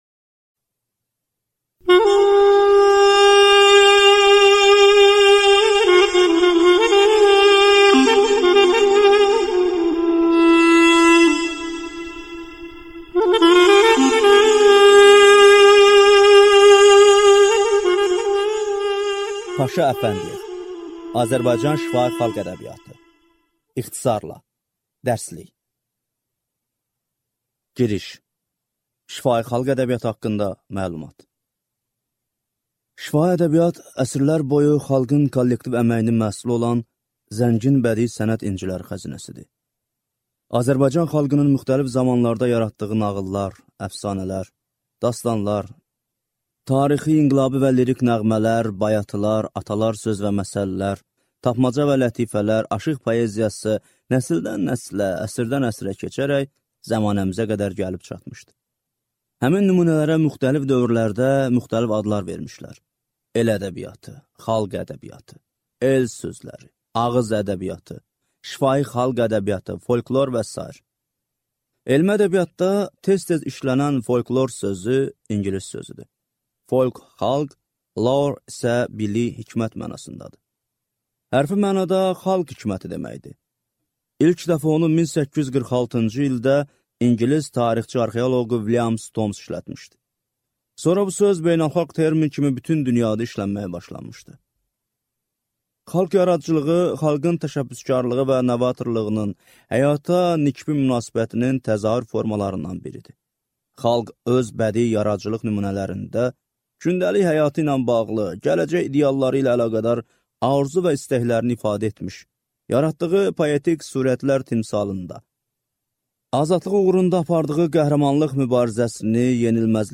Аудиокнига Azərbaycan şifahi xalq ədəbiyyatı | Библиотека аудиокниг